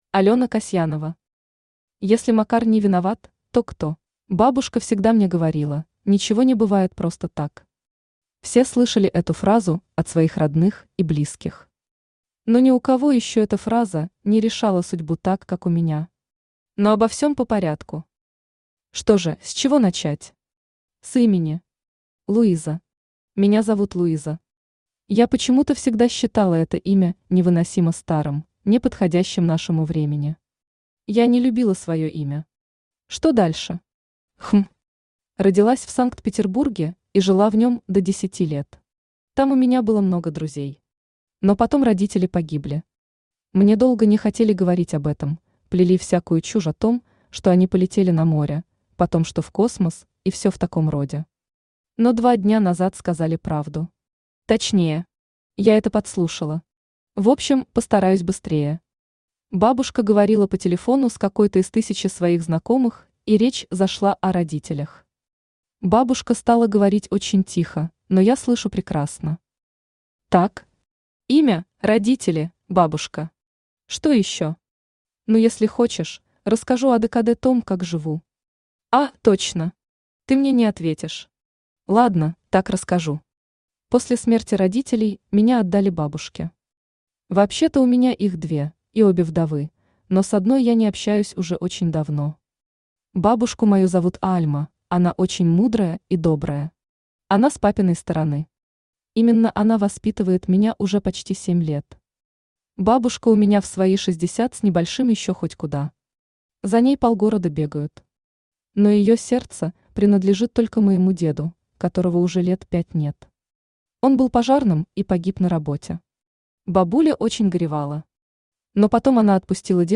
Автор Алёна Фёдоровна Касьянова Читает аудиокнигу Авточтец ЛитРес.